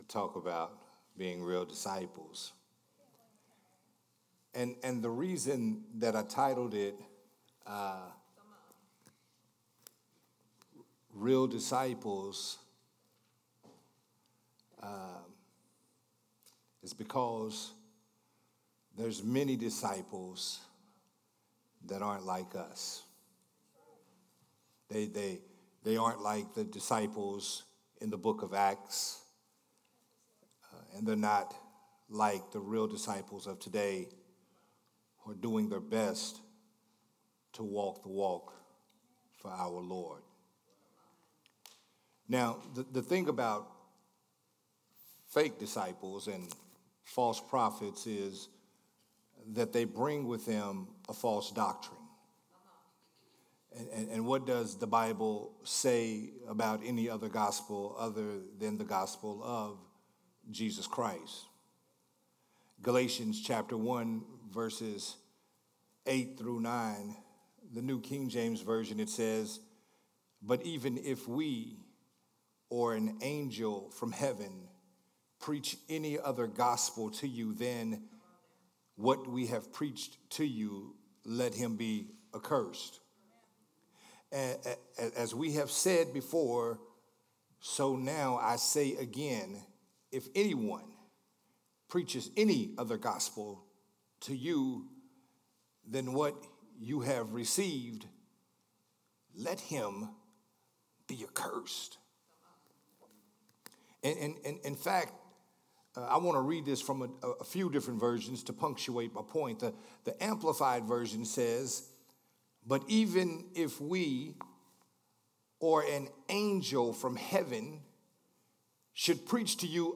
Growth Temple Ministries